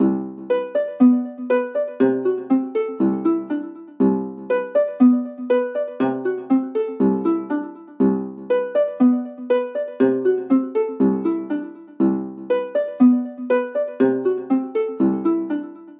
描述：电子竖琴环（120 bpm）
Tag: 竖琴 循环 字符串 电子合成器 ARP